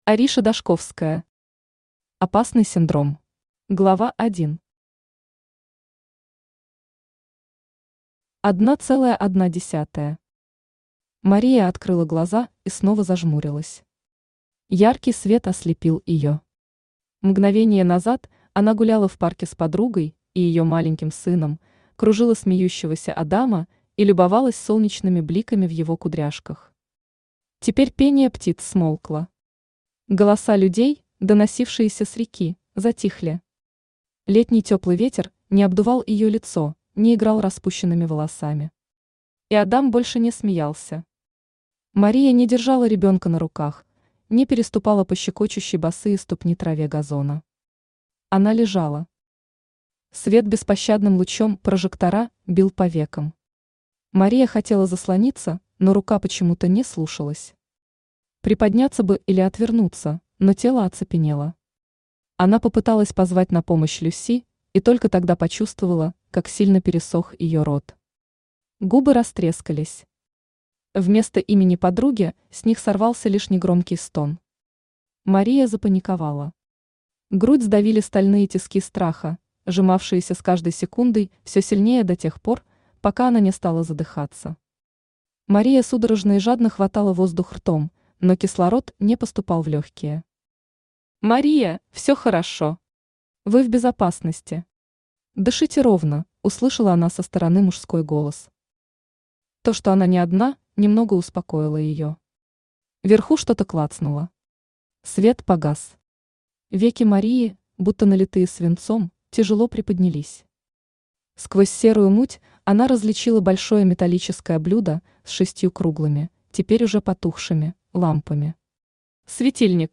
Аудиокнига Опасный синдром | Библиотека аудиокниг
Aудиокнига Опасный синдром Автор Ариша Дашковская Читает аудиокнигу Авточтец ЛитРес.